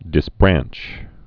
(dĭs-brănch)